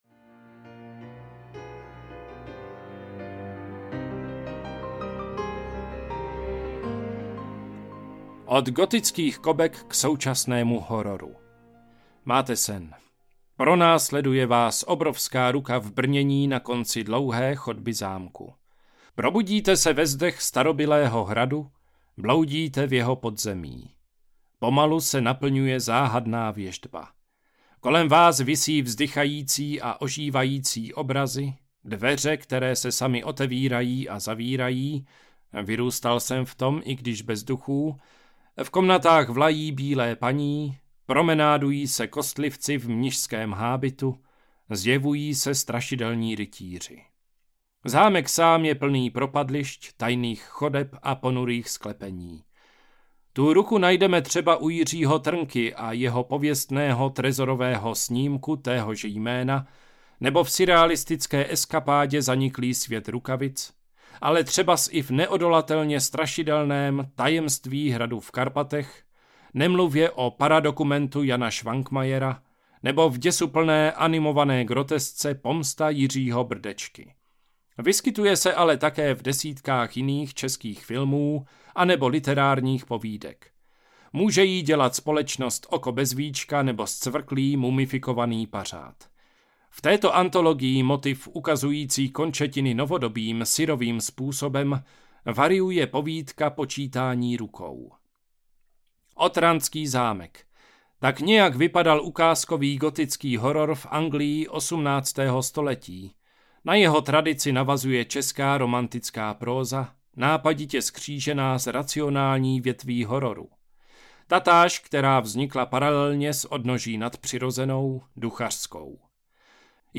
České temno audiokniha
Ukázka z knihy
ceske-temno-audiokniha